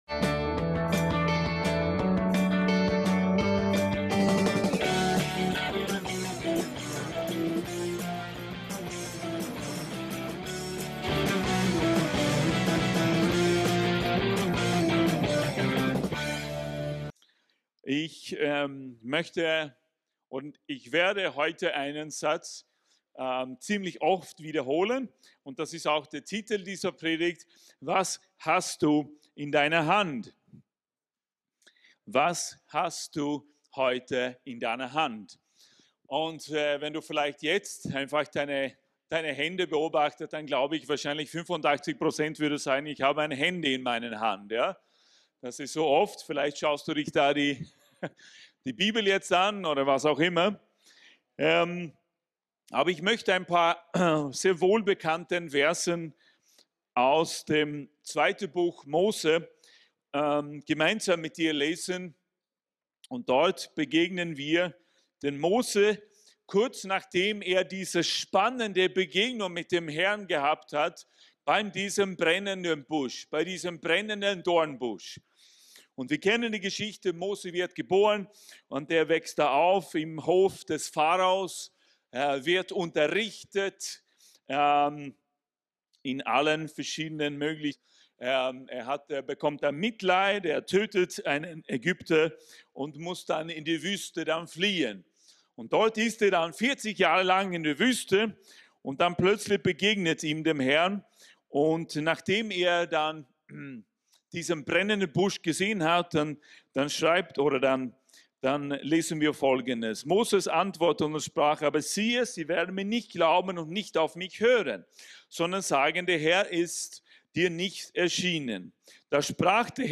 WAS HAST DU IN DEINER HAND ~ VCC JesusZentrum Gottesdienste (audio) Podcast